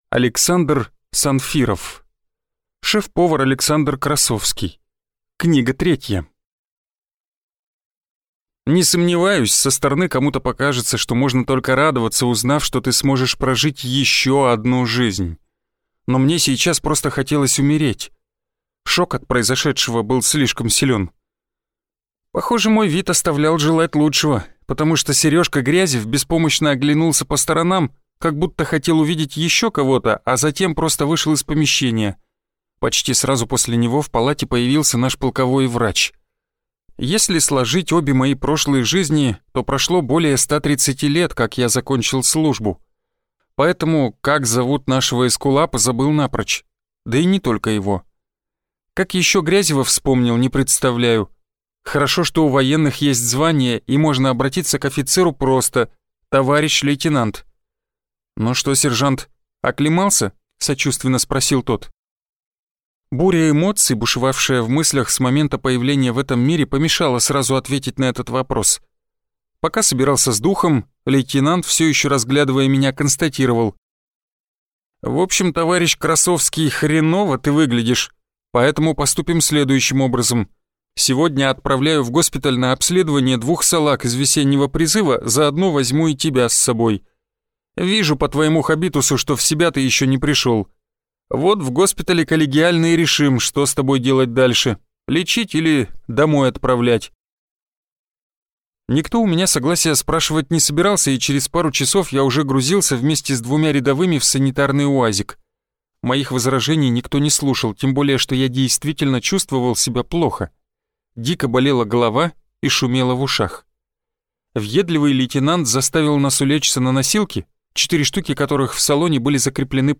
Аудиокнига Шеф-повар Александр Красовский 3 | Библиотека аудиокниг